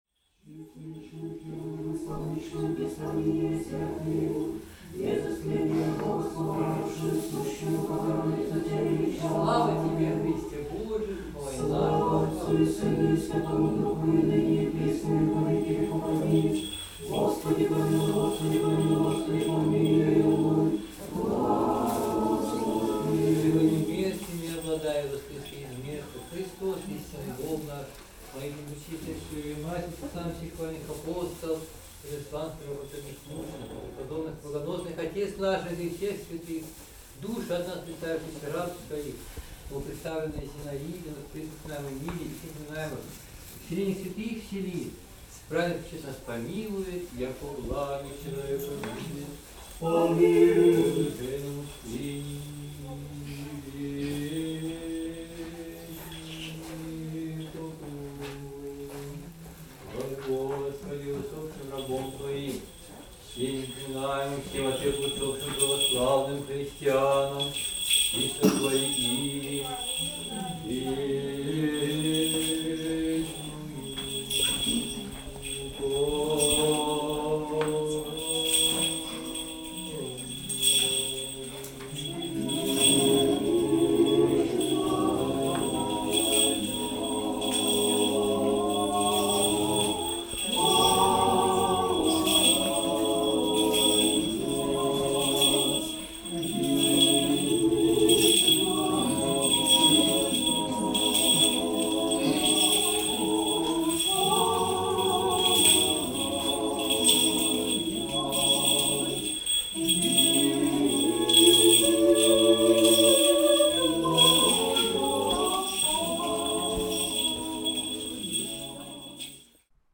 Фрагмент службы в сельской церкви и колокольный звон
Практически не обрабатывал.
Колокольня стоит рядом с храмом (на фото не попала).
Лития+Колокольный звон.mp3